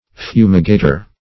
Fumigator \Fu"mi*ga`tor\, n.